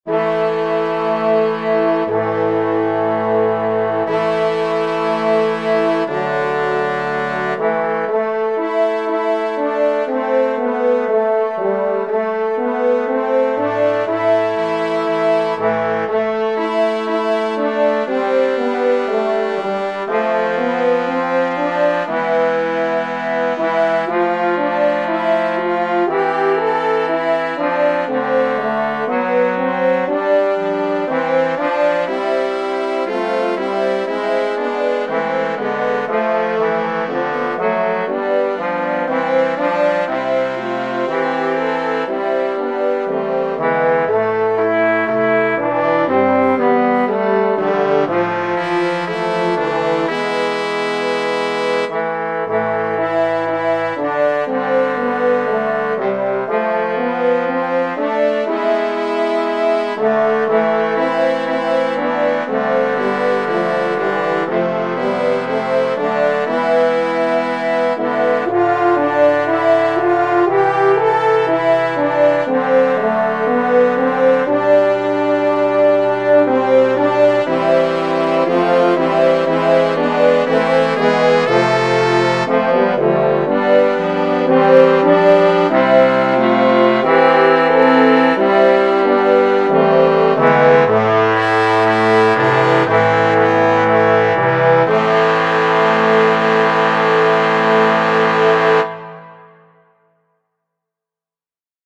Clarinet, Instrument Ensemble, Trombone, Trumpet
Voicing/Instrumentation: Clarinet , Instrument Ensemble , Trombone , Trumpet We also have other 13 arrangements of " God Rest Ye Merry Gentlemen ".